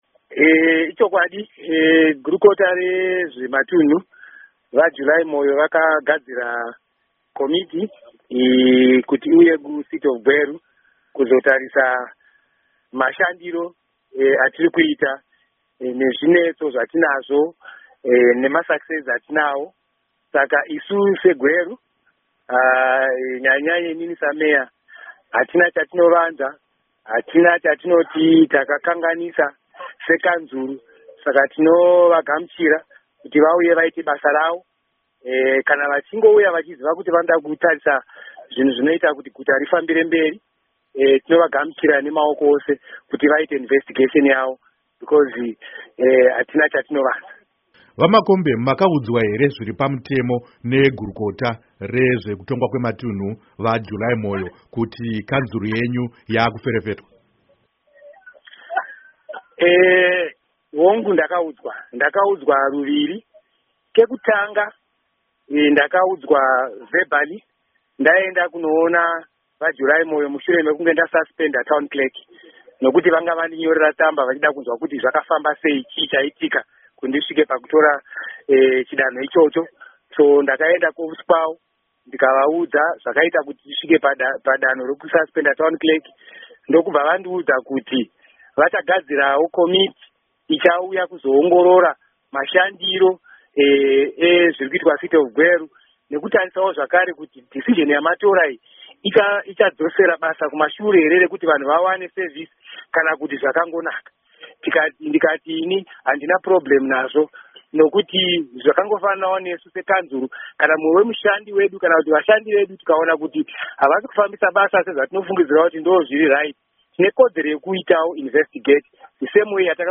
Hurukuro naVaJosiah Makombe